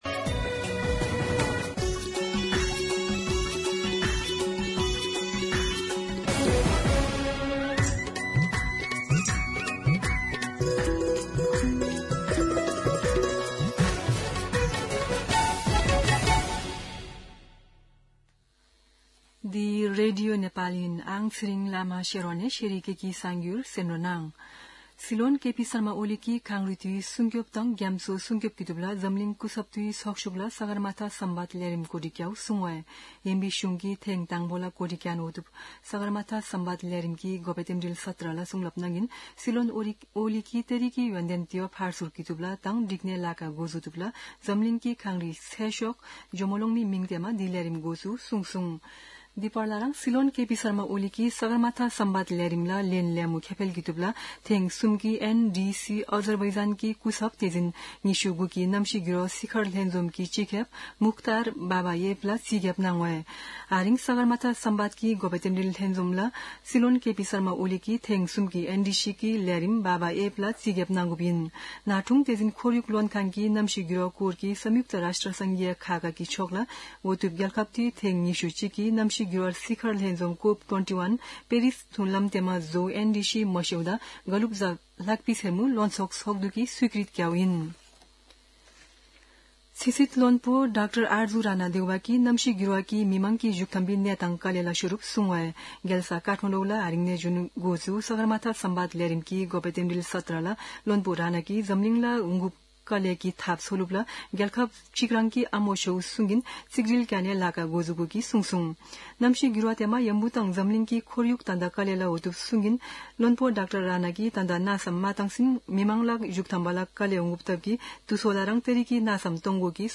शेर्पा भाषाको समाचार : २ जेठ , २०८२
Sherpa-News-02-2.mp3